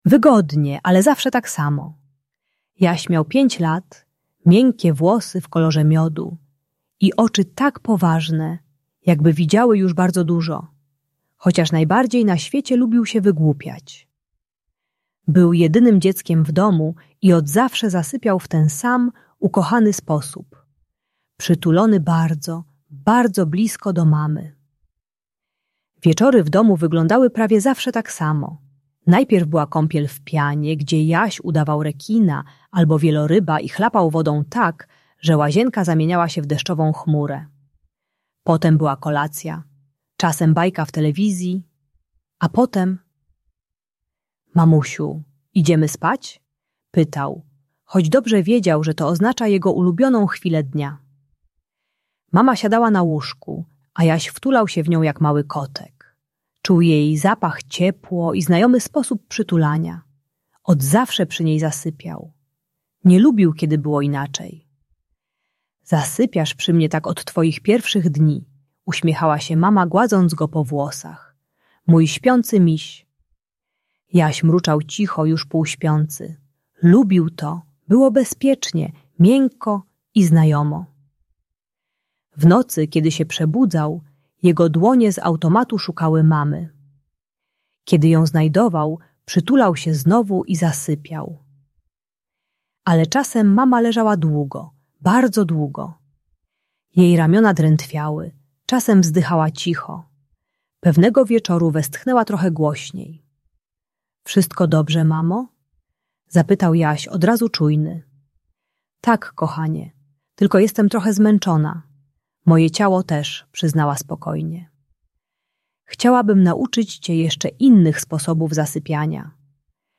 Jaś i Namiot Spokojnego Snu - Usypianie | Audiobajka